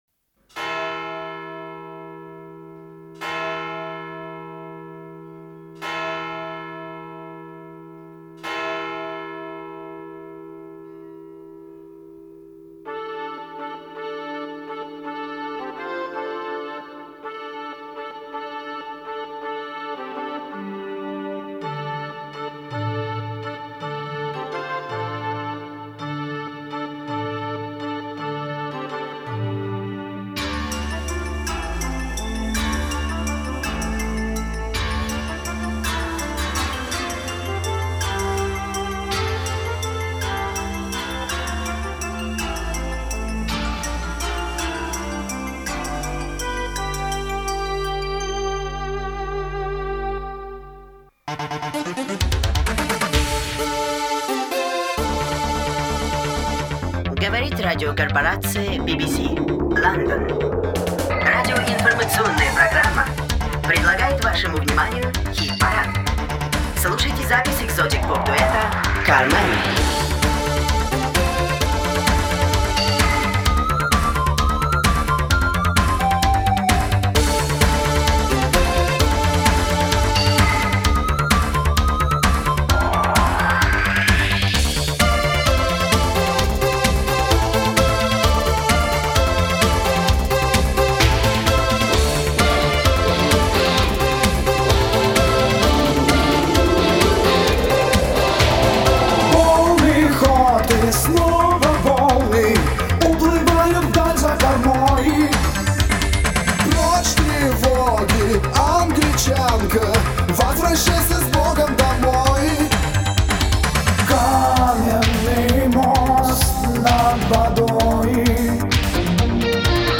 Музыкальный жанр: поп, ретро (диско 80-х)